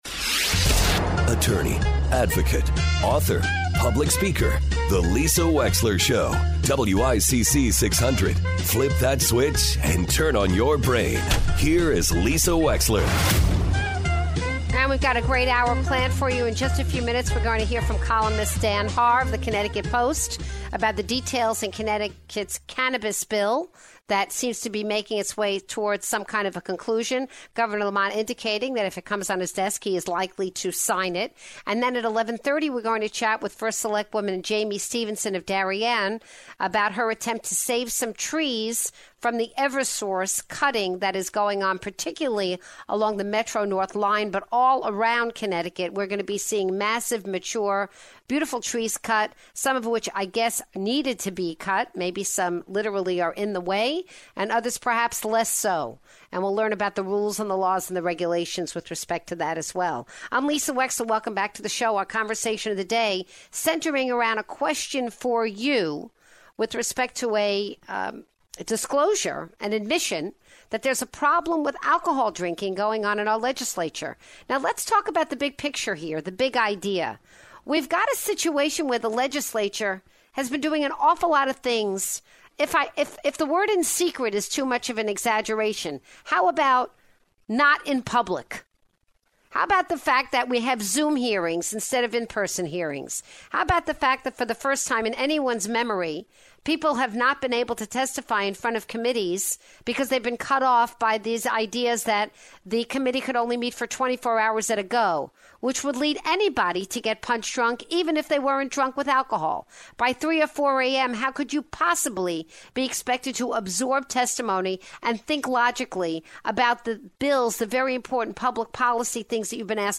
Drinking and Legislating? Callers Sound Off - 06/03/21